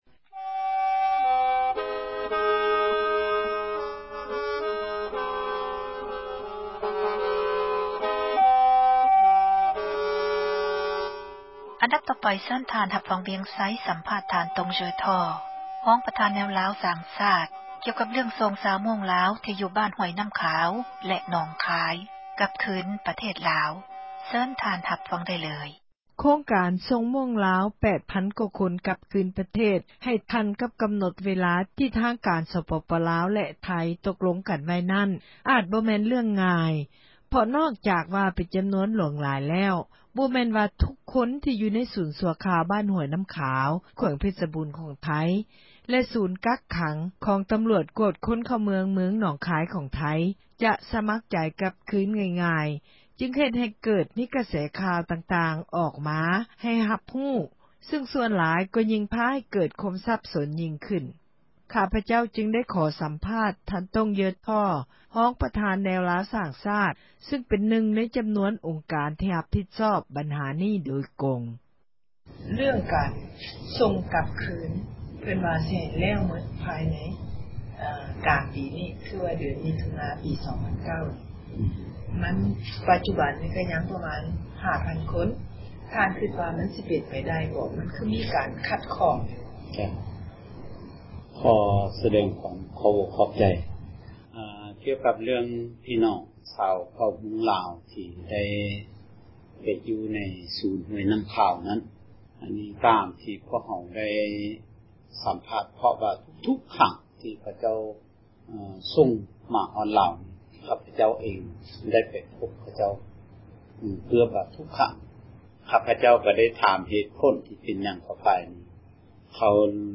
ສຳພາດ ທ່ານ ຕົງເຢີທໍ